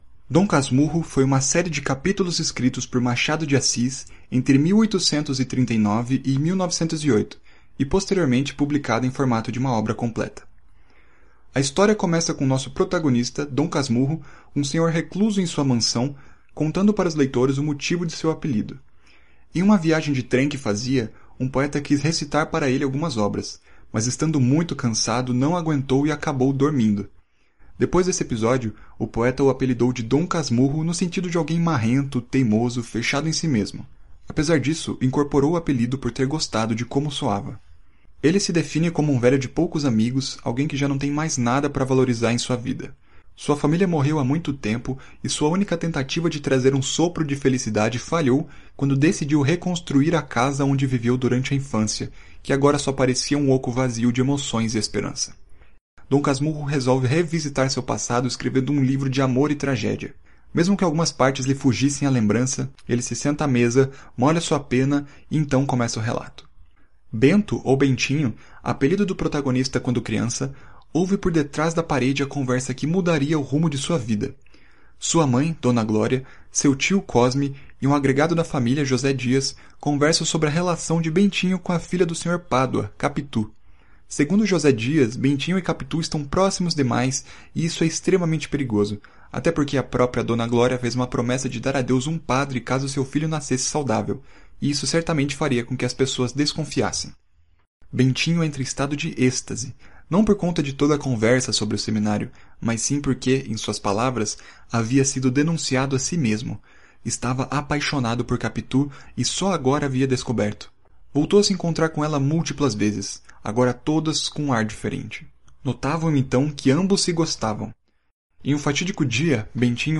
Resumo do Livro Dom Casmurro em Áudio, Ouça aqui o resumo do livro em Audiobook completo, Aperte o Player para Ouvir...